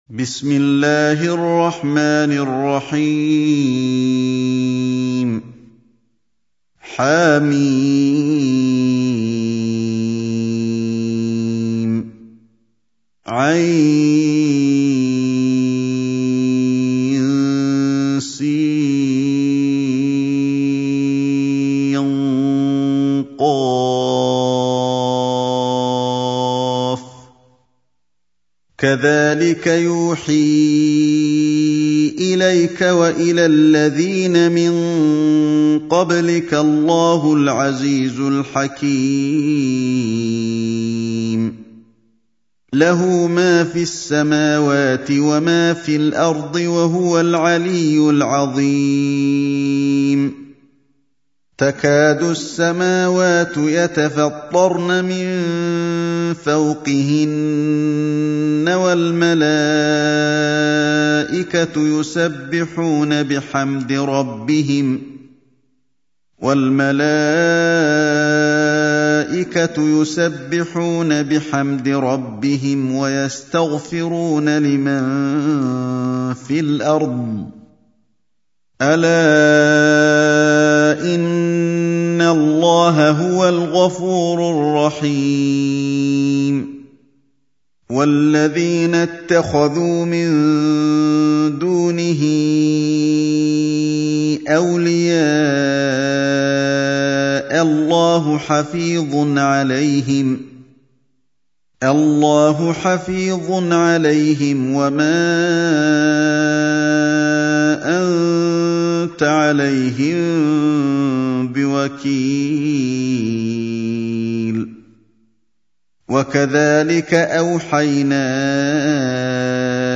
سورة الشورى | القارئ علي الحذيفي